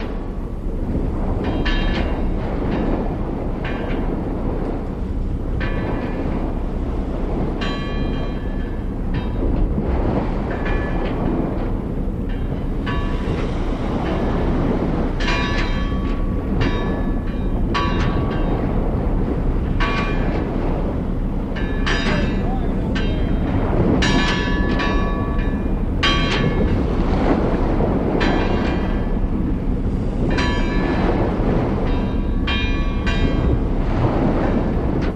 Harbor Atmosphere Buoy Bell And Hum